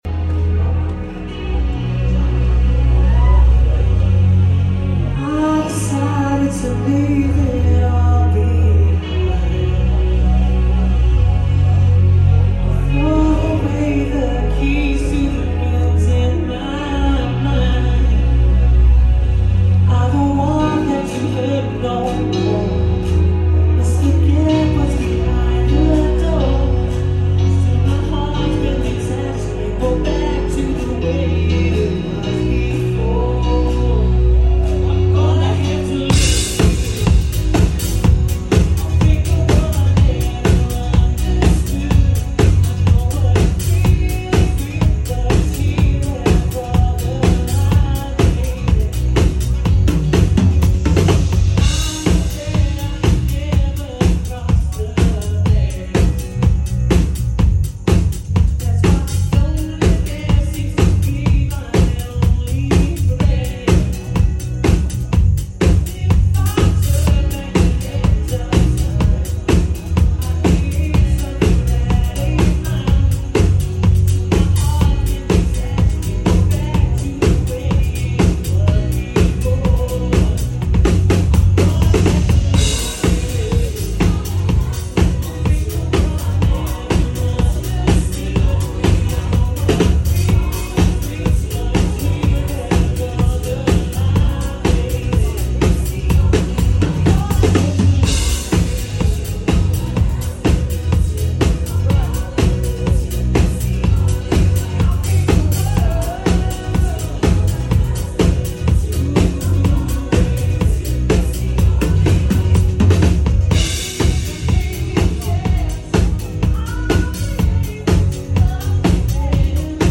in Maastricht